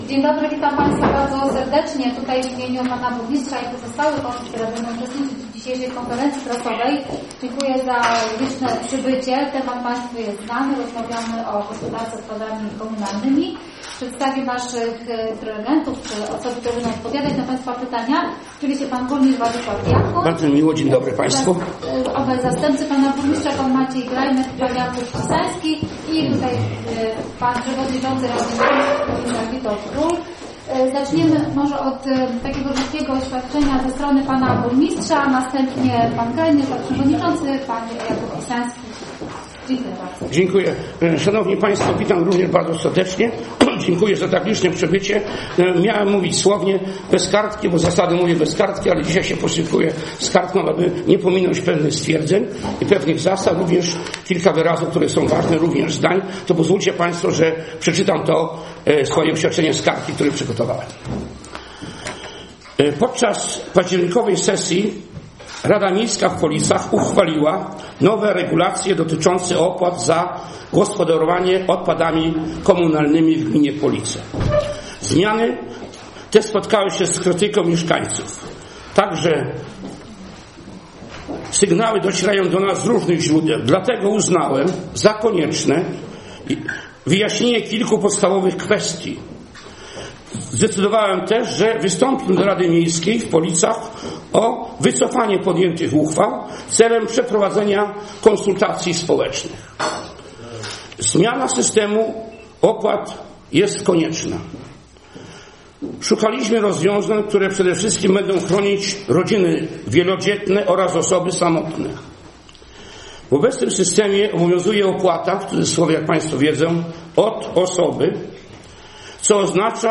Konferencja prasowa
Władysław Diakun burmistrz Polic